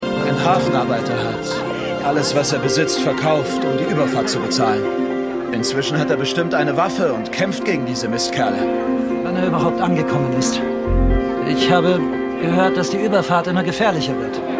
Pachinko_2x07_KoreanischerAktivist_KoreanischerLandsmann.mp3